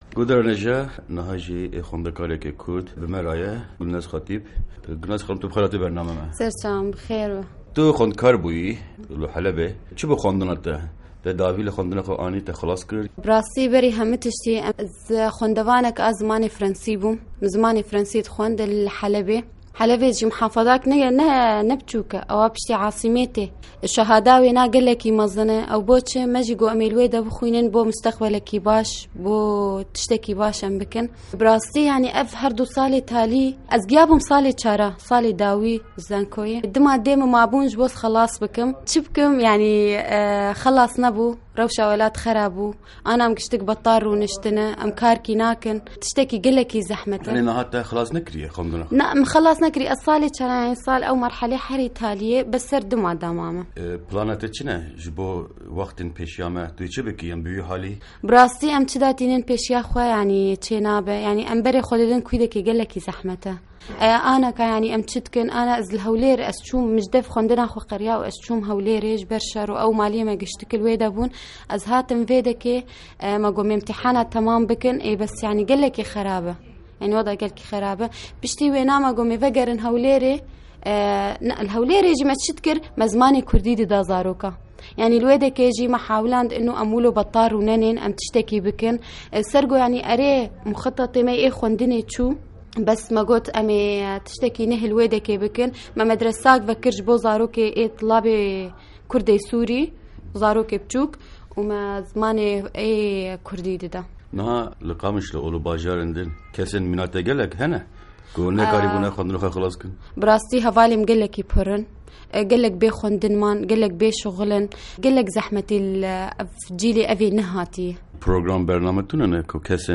hevpeyvînê